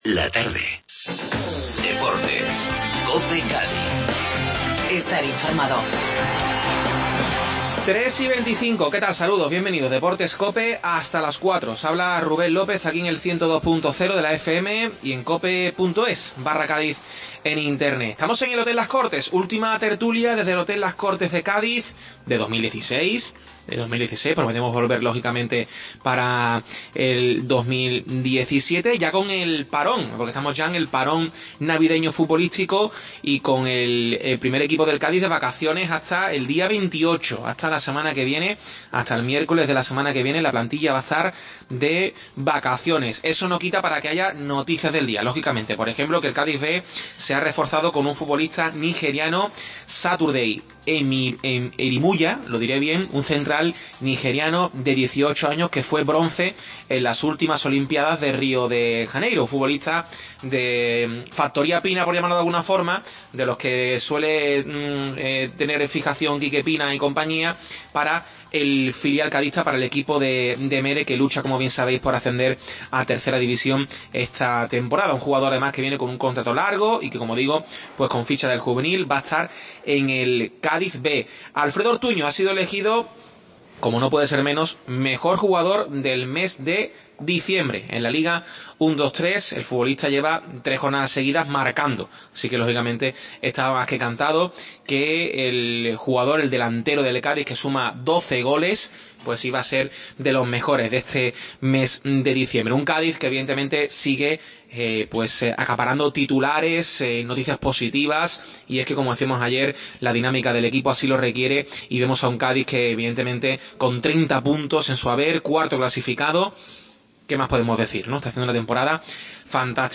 Desde el Hotel Las Cortes tertulia